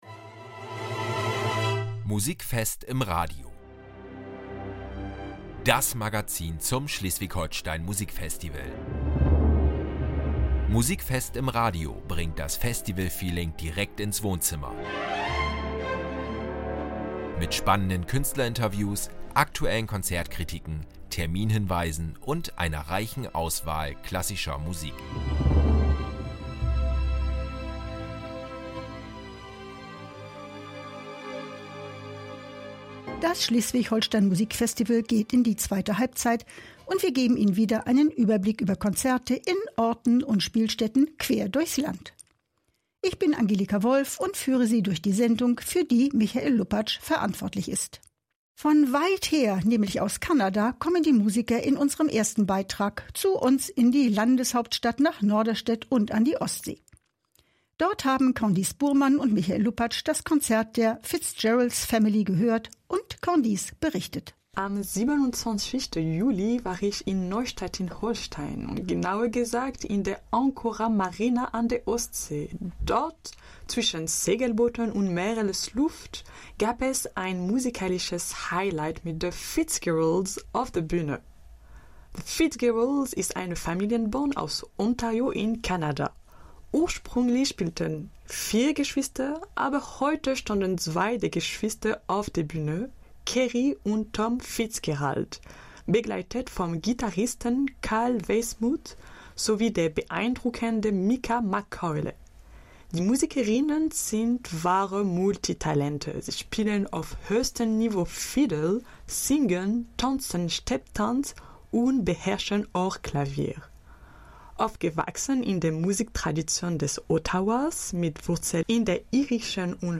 Beschreibung vor 7 Monaten In der sechsten Ausgabe von Musikfest im Radio erwarten euch spannende Gespräche mit der britischen Saxofonistin Jess Gillam und dem litauischen Akkordeonvirtuosen Martynas Levickis. Dazu gibt es Konzertkritiken zum Auftritt von “The Fitzgeralds” in Neustadt und dem Projekt “Grenzenlos” in der Lübecker Wichern-Kirche, einen Bericht über mitreißende Balkan-Beats aus Istanbul auf der Kulturwerft Gollan sowie Eindrücke von “Faszination Blechbläser” und “Mozart im Morgenland”.